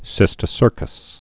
(sĭstĭ-sûrkəs)